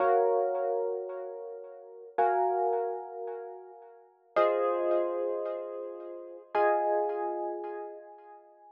03 ElPiano PT1.wav